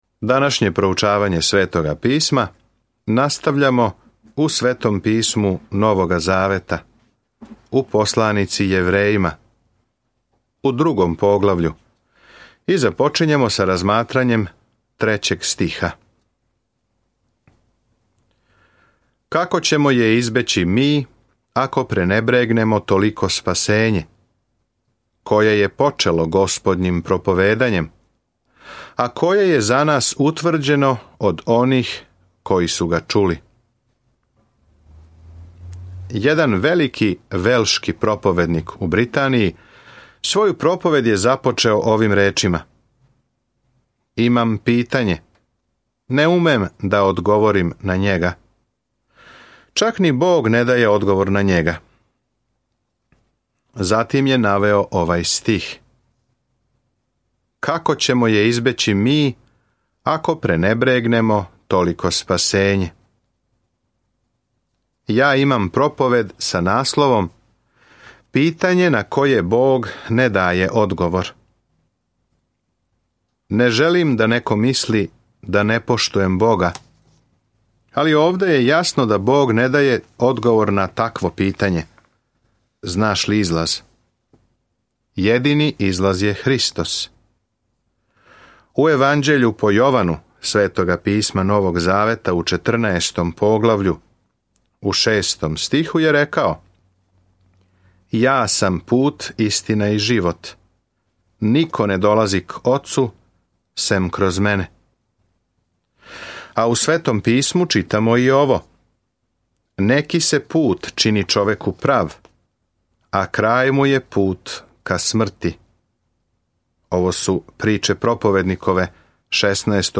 Свакодневно путујте кроз Јевреје док слушате аудио студију и читате одабране стихове из Божје речи.